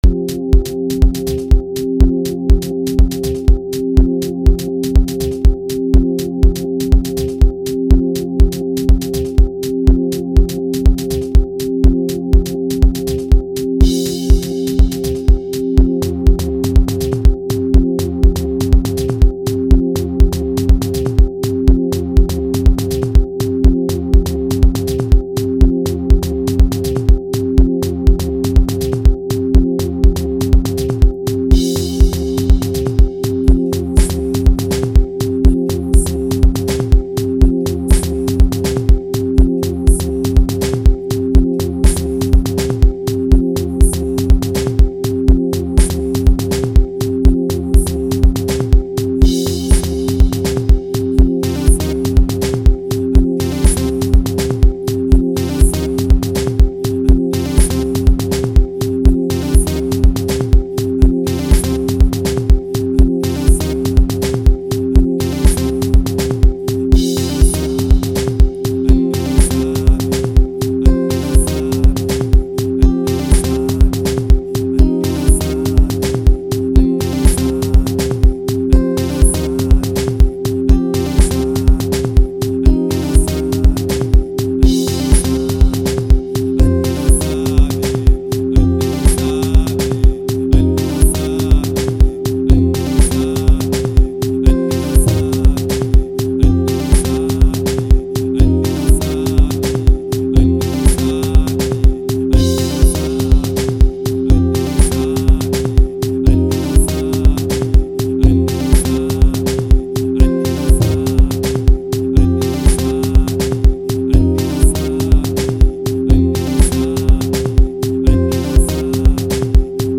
05:31 Genre : Deep House Size